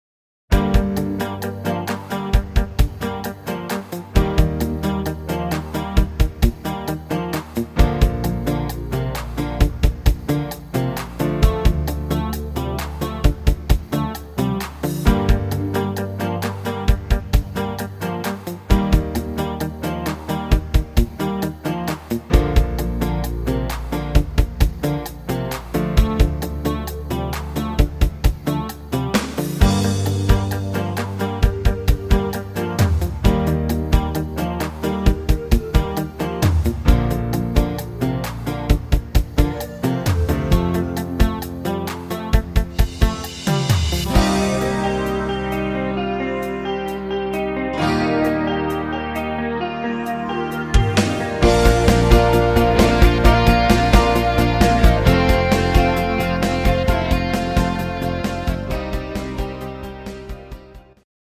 Rock-Pop aus der Schweiz